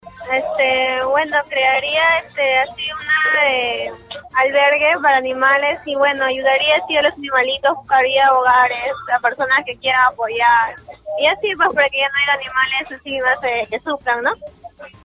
Debido a la gran cantidad de perros en las calles de Villa El Salvador, vecinos opinaron a través de radio Stereo Villa respecto a las alternativas que deberían plantearse para reducir el incremente de canes que deambulan por el distrito.
Así mismo, una de los pobladores comentó que debería existir una albergue o casa hogar en el distrito para los perros abandonados, ya que sería la posible solución para disminuir la cantidad de dichos animales
AUDIO-3-ALBERGUE.mp3